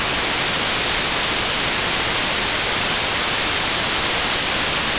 Joonisel 3.4 on valge müra helilaine,
Mitteperioodiline heli, mida tajume mürana.
valge_myra.wav